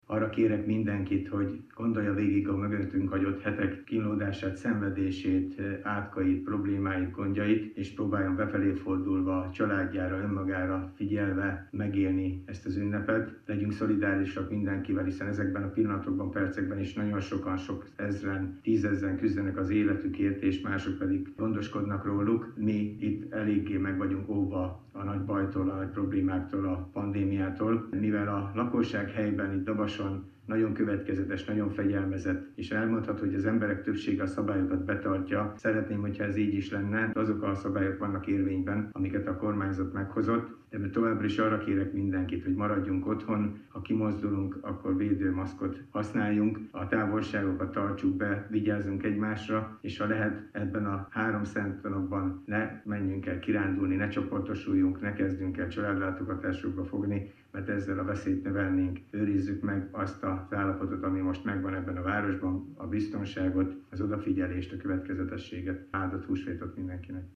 Ugyan húsvét van, mégsincs itt a családlátogatás ideje - ezt mondta Kőszegi Zoltán, Dabas Város polgármestere ünnepi köszöntőjében. A városban egyelőre kevés a beteg, azonban most is vannak olyanok szerte a világban, akik az életükért küzdenek, vagy a betegeket ápolják.